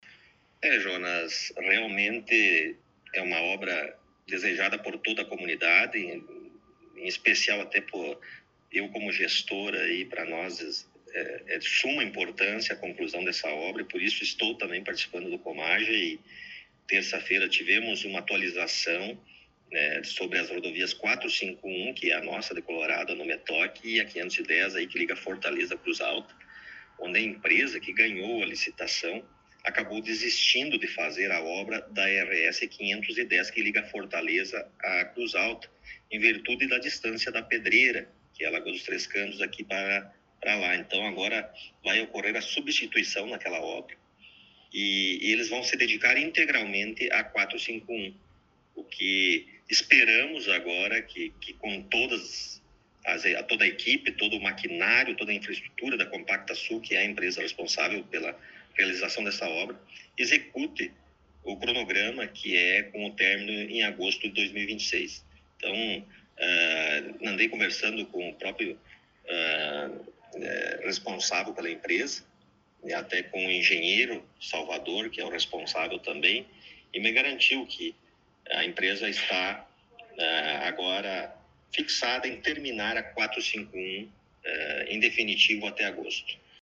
Entrevista com o Prefeito Rodrigo Sartori: IPTU 2026 e Obras no Município
Na última semana, em um encontro no gabinete da prefeitura, tivemos a oportunidade de entrevistar o prefeito Rodrigo Sartori, logo após seu retorno das férias.